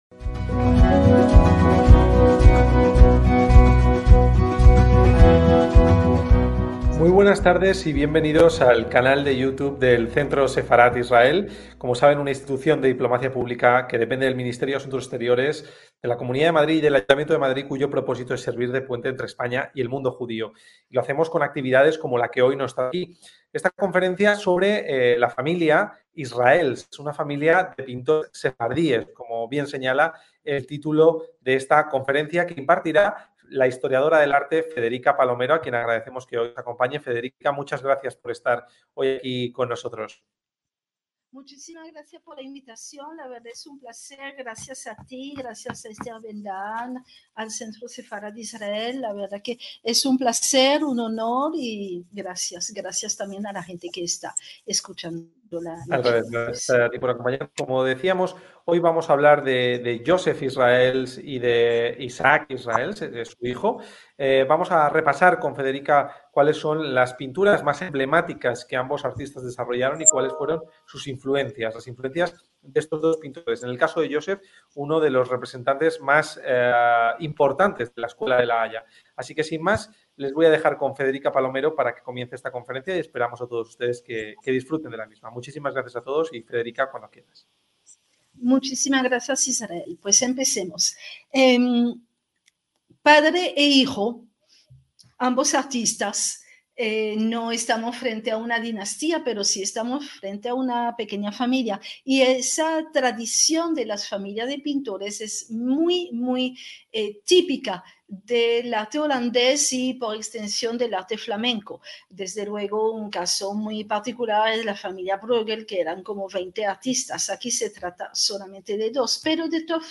ACTOS "EN DIRECTO" - El pintor sefardí holandés Jozef Israëls fue uno de los artistas más reputados internacionalmente de su época.